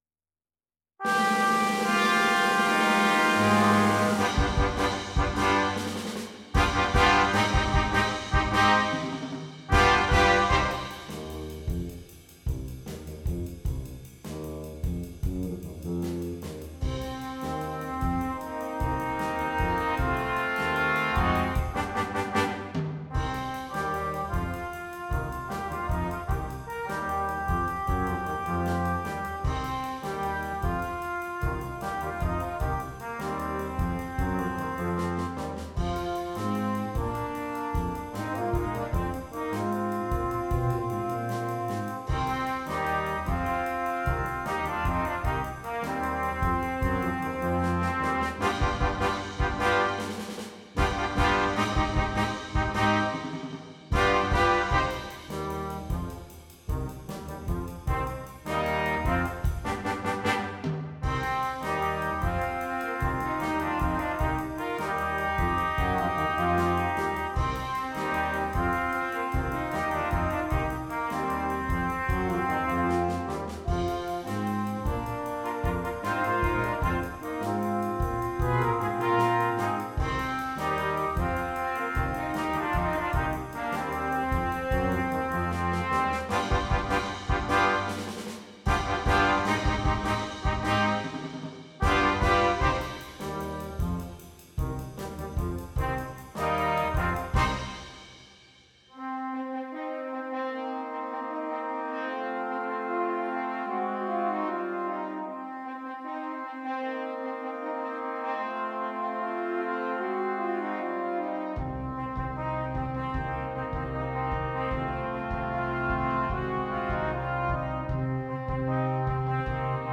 Voicing: Brass Quintet